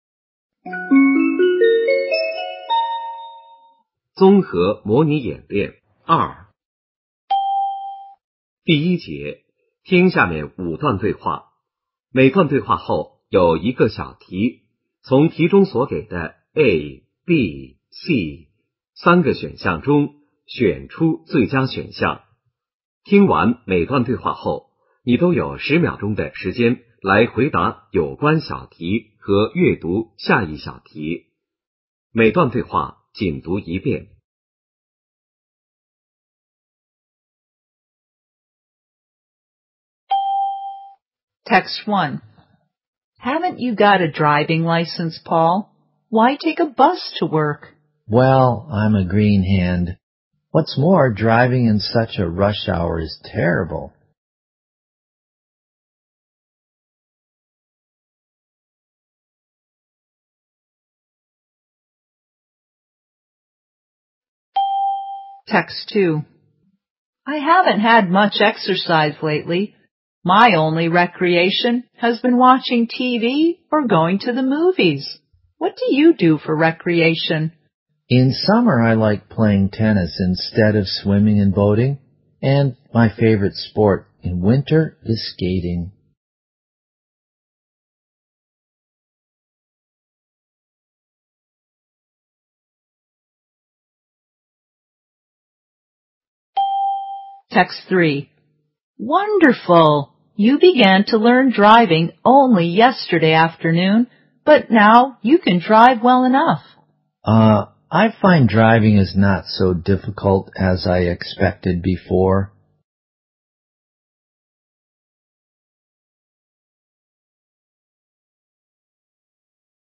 听力与训练